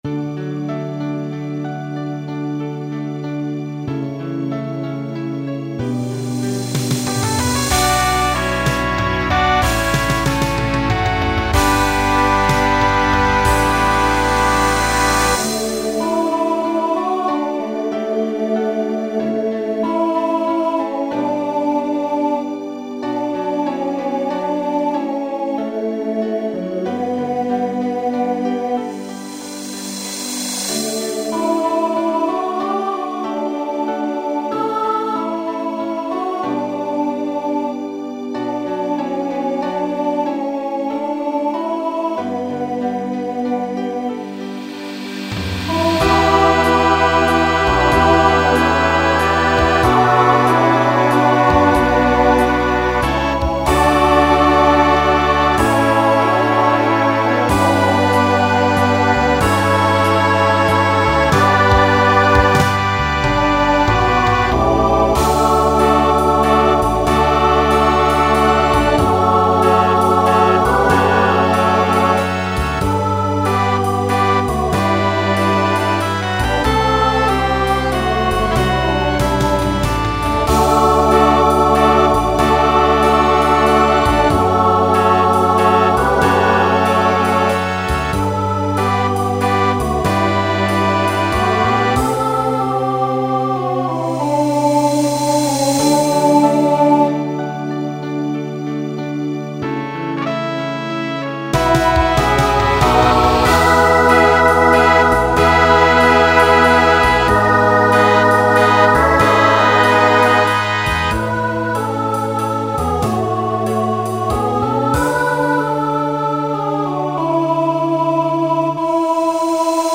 Genre Rock
Costume Change Voicing Mixed